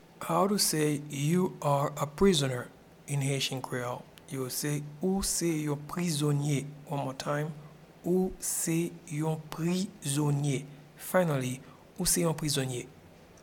Pronunciation and Transcript:
You-are-a-prisoner-in-Haitian-Creole-Ou-se-yon-prizonye.mp3